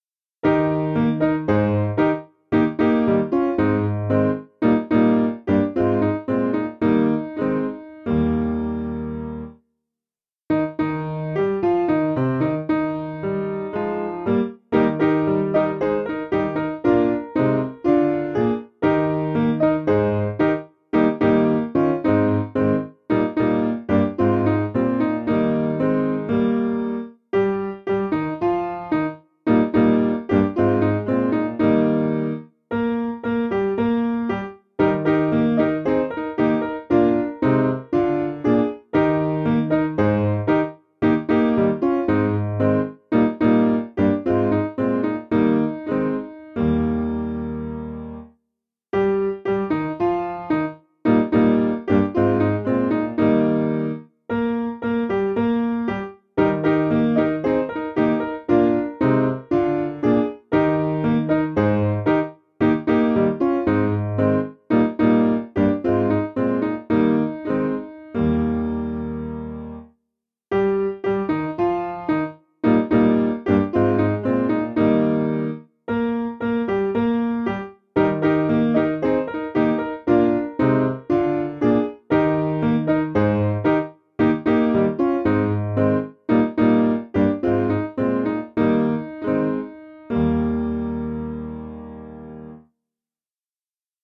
Key: D Major
African-American spiritual